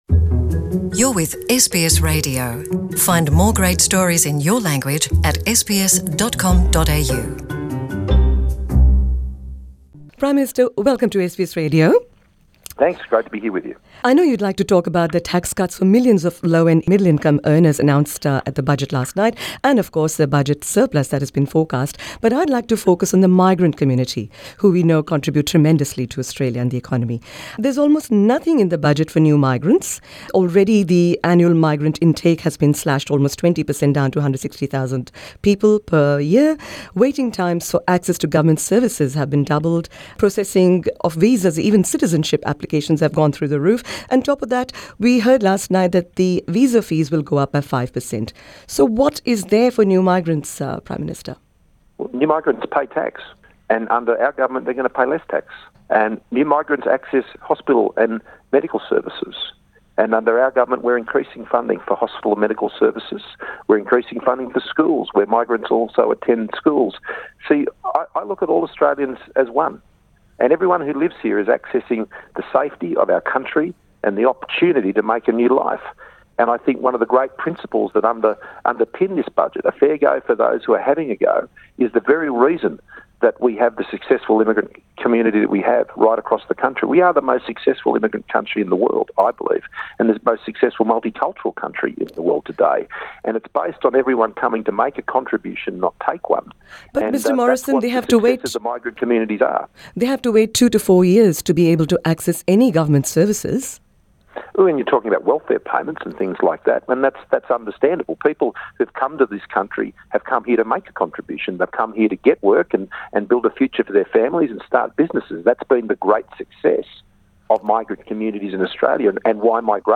L’interview du Premier ministre Scott Morrison sur le budget 2019